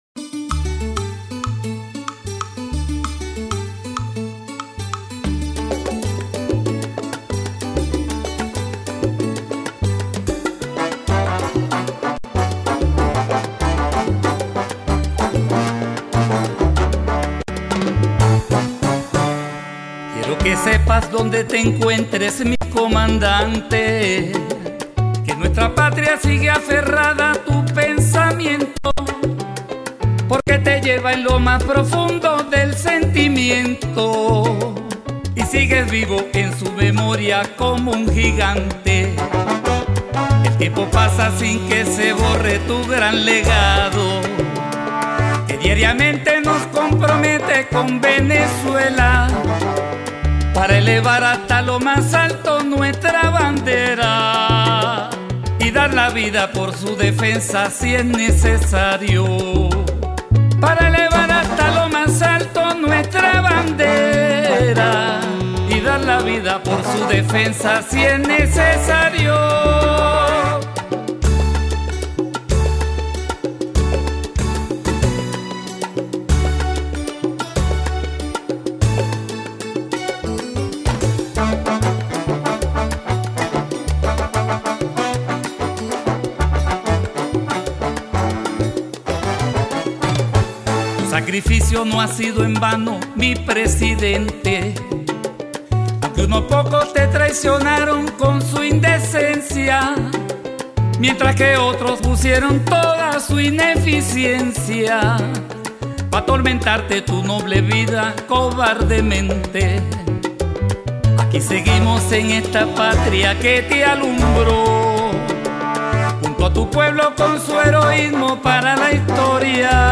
Mi homenaje a Chávez en sus 71 años en letra y música titulado *Donde Te Encuentres* (salsa)